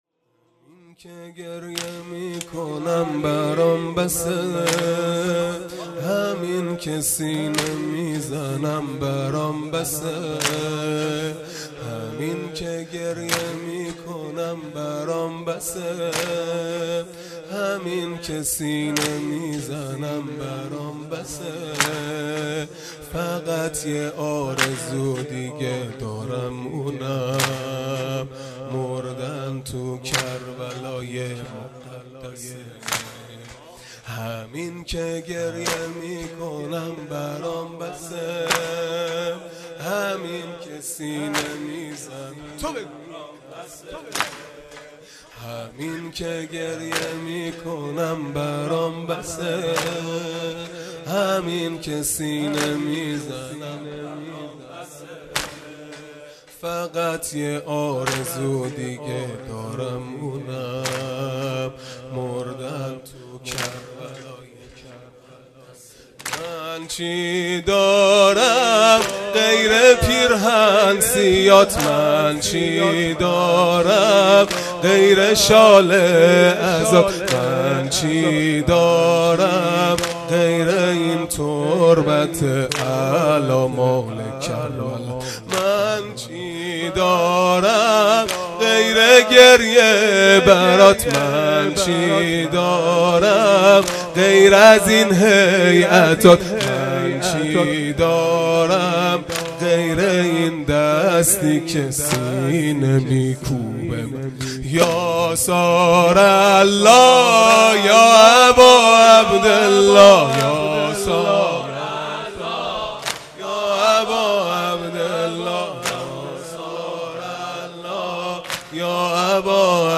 خیمه گاه - هیئت بچه های فاطمه (س) - واحد | همین که گریه میکنم برام بسه
فاطمیه دوم(شب چهارم)